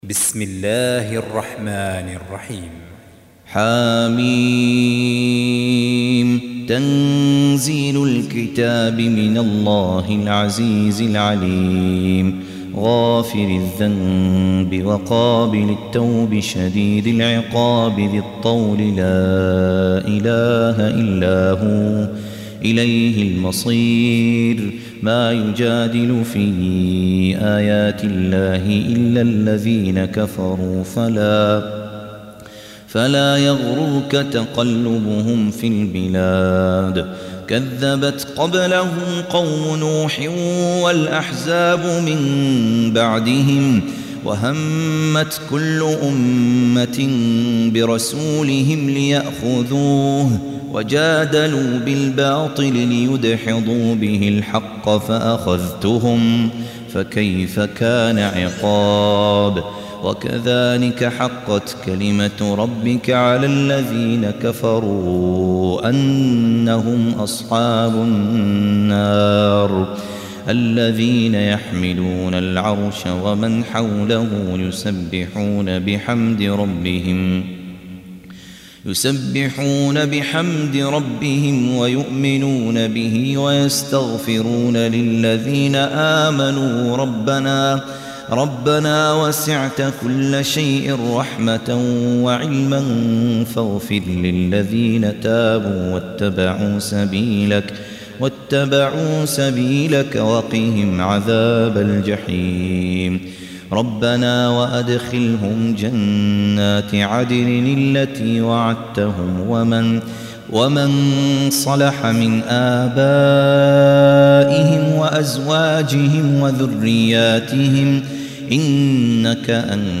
Surah Repeating تكرار السورة Download Surah حمّل السورة Reciting Murattalah Audio for 40. Surah Gh�fir سورة غافر N.B *Surah Includes Al-Basmalah Reciters Sequents تتابع التلاوات Reciters Repeats تكرار التلاوات